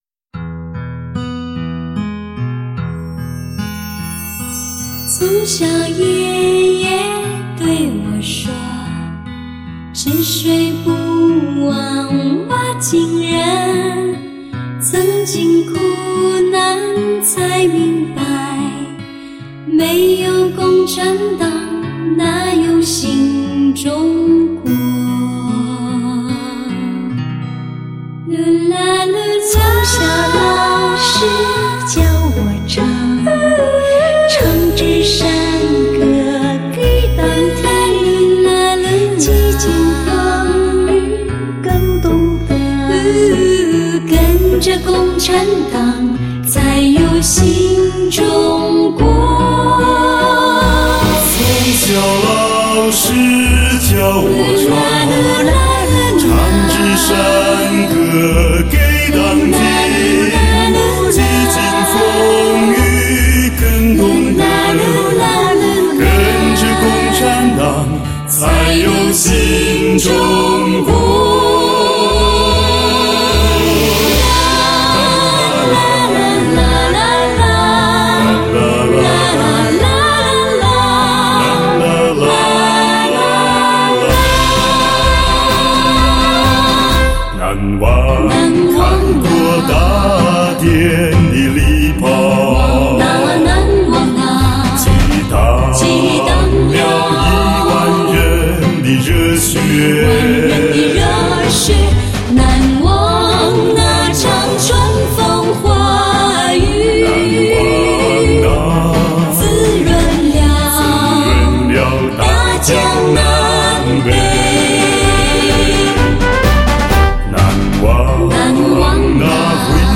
岁月的沉淀，心灵的交织，用心聆听全新演绎下的中国经典红色之歌。
全新演绎经典，创世纪传奇的HI-FI音乐天碟。